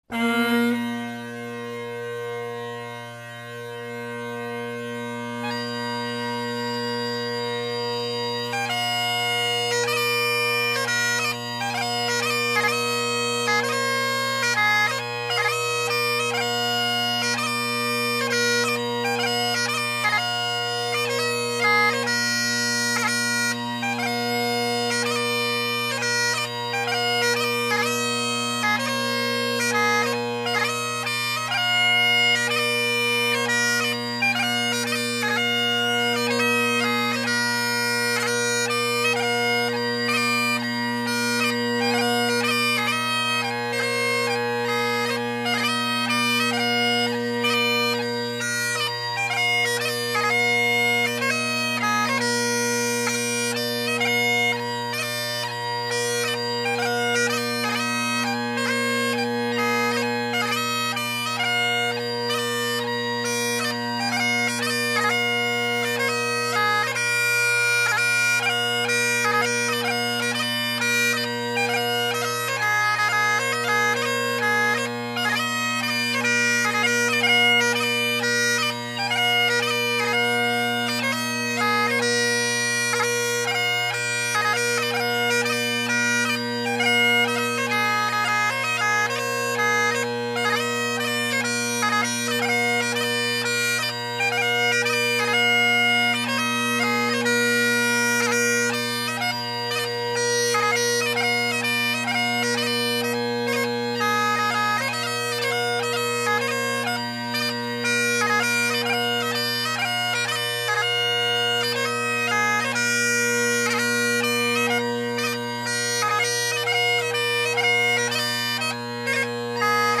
Great Highland Bagpipe Solo